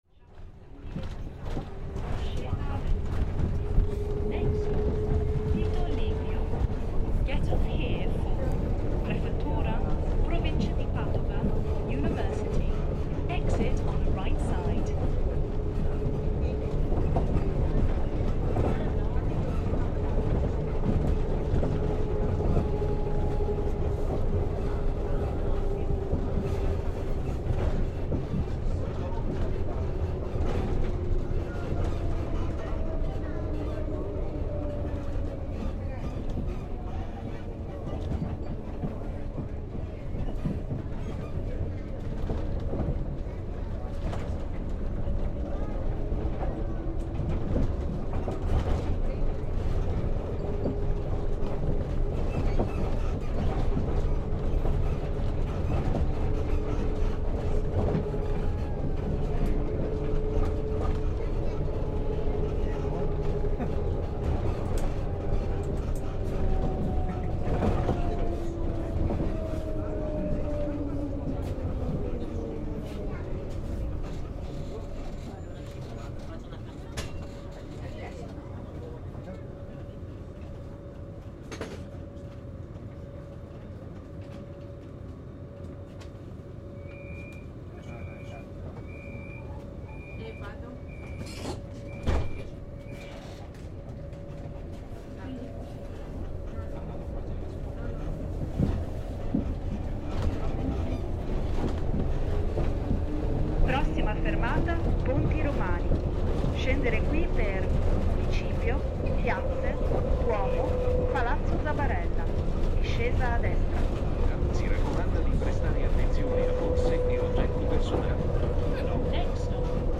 Riding the tram from Santo
All aboard the fairly-new Padova tram system, which ferries people calmly and unfussily around this splendid northern Italian city.